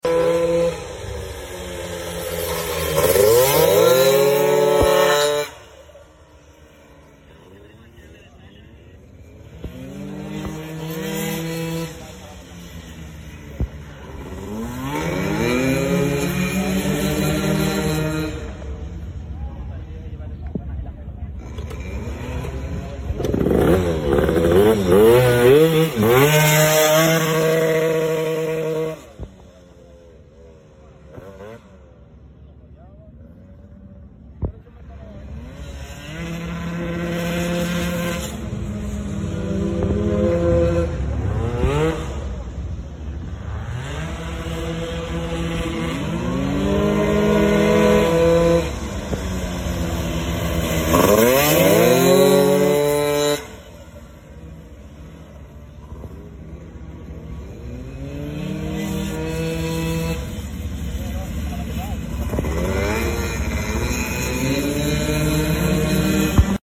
Honda dio 125cc racing two sound effects free download
Honda dio 125cc racing two stroke motorcycle & scooter